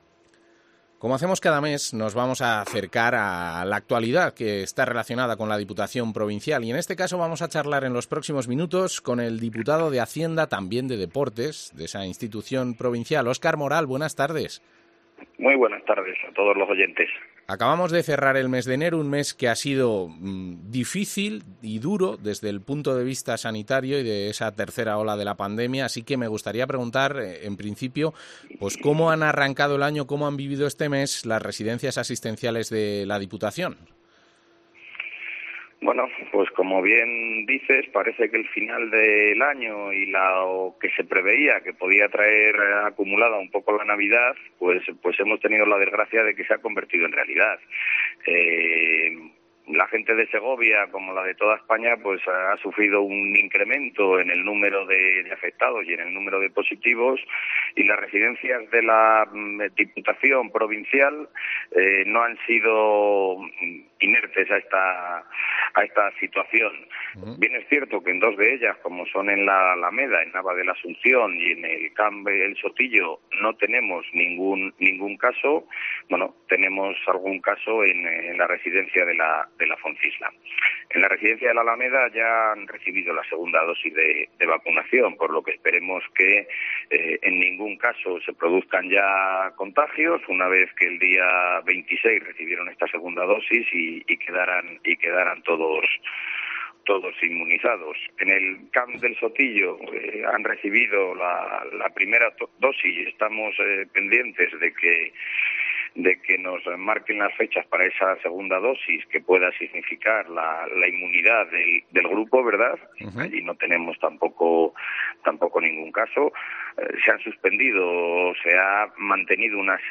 Entrevista al diputado provincial, Óscar Moral